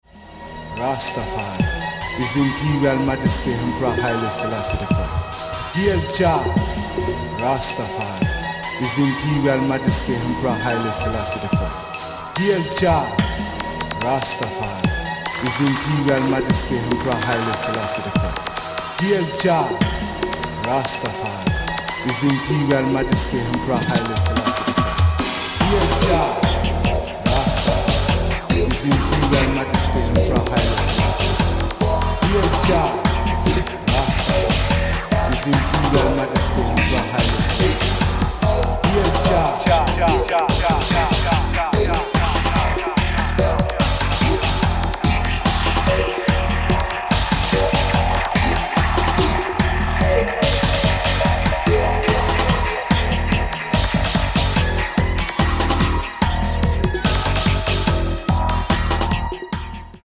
Raw reggae/dub from Brasil.